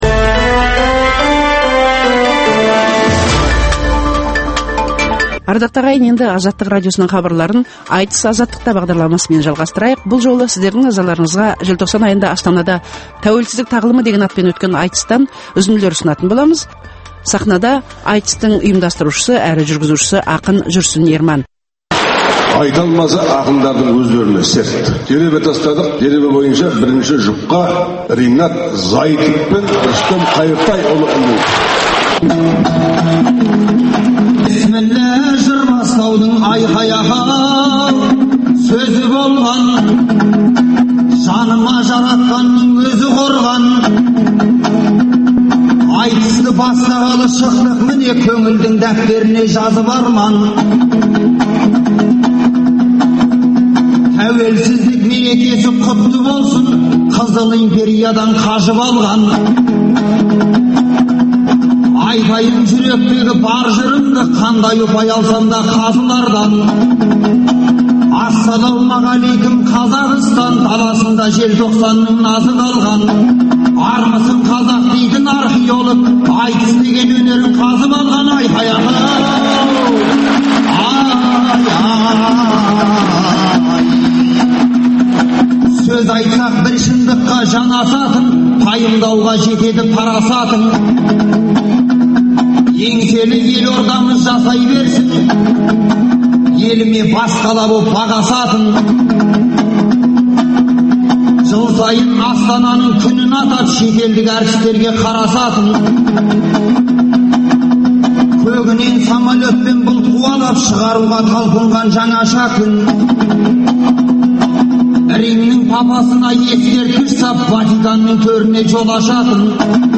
Айтыс - Азаттықта
Биліктің қамқорлығына өткен айтыс өткен жыл бойы аудандық, облыстық, қалалық деңгейде ұйымдастырылып, қорытынды сөз додасы Қазақстанның тәуелсіздік күніне орай Астанада болды. Азаттық радиосы «Тәуелсіздік тағылымы» деген атпен үш күн бойы өткен ақындар айтысын жүйелі түрде беріп отырмақ.